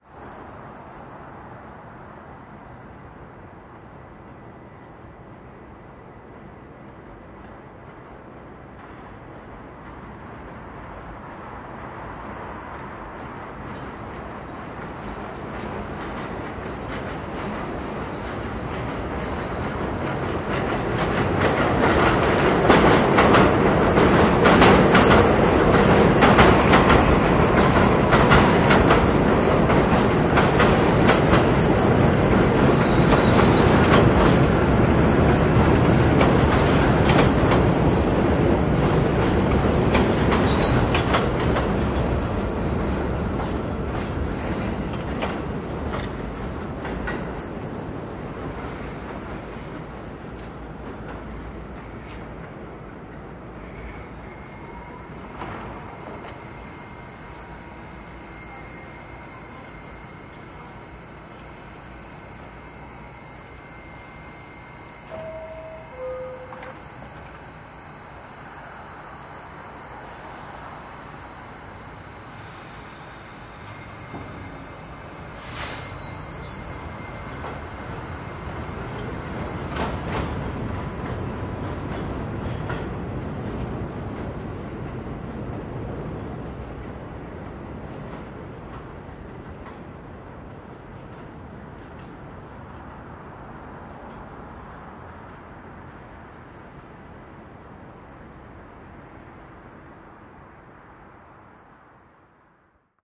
附近的火车站氛围，火车来来往往的长龙
描述：清晨从我的阳台上记录下来，这是一个火车站对面的路。一般的氛围，鸟类和一些火车来来往往。用Tascam DR100录制。
标签： 氛围 火车 背景 街道 安静 自然 现场记录 列车站
声道立体声